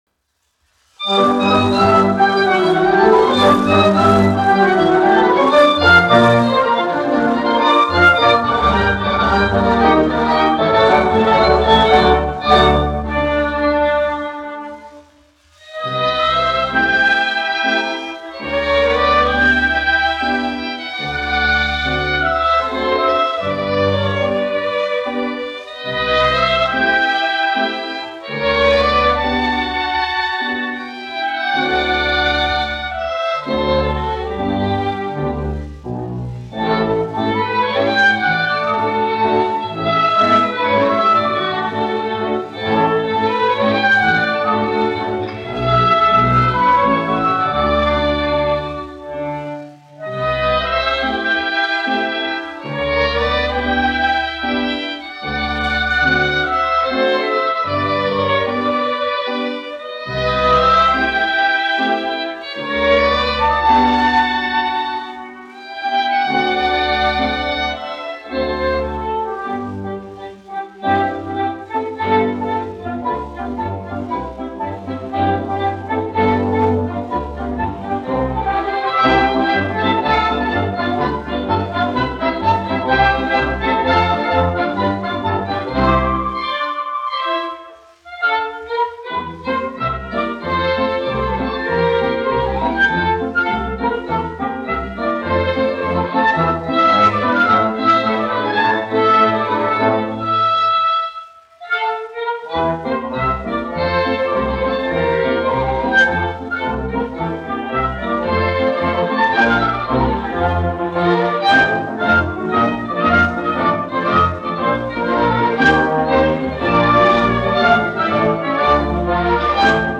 1 skpl. : analogs, 78 apgr/min, mono ; 25 cm
Popuriji
Operetes--Fragmenti
Orķestra mūzika
Skaņuplate